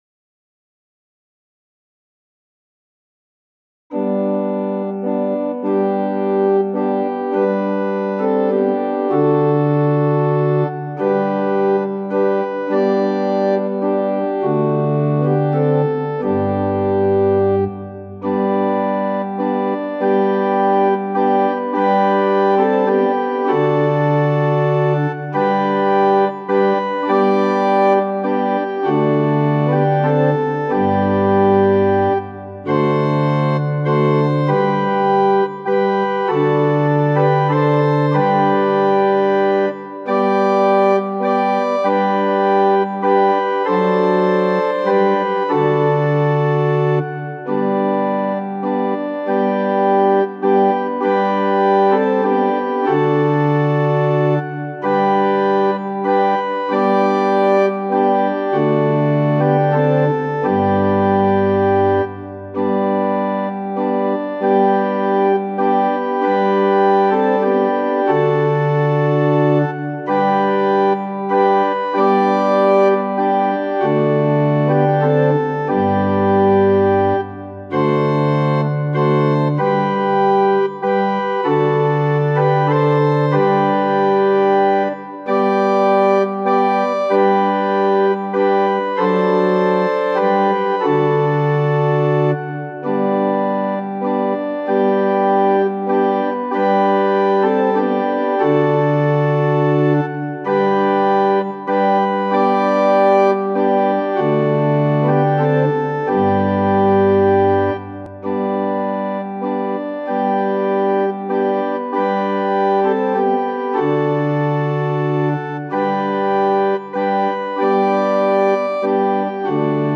��^���p�I���K�����t�����F �@�@�E�O�t������܂� �@�@�E�ԑt�͊܂܂�Ă��܂��� ���������I�ɉ���������Ȃ��ꍇ�A�������N���b�N�� ���FJohn Newton, 1725-1807 �ȁFMarcus M. Wells, 1815-1895 Tonality = G Pitch = 440 Temperament = Equal �� 92, 133 �� 6:24-26 �w�u 4:9